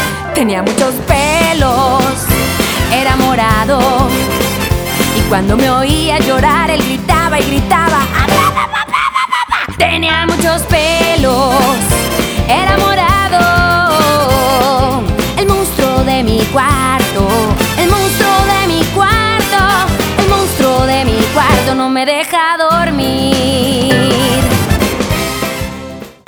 In 2009, she released another new children's album.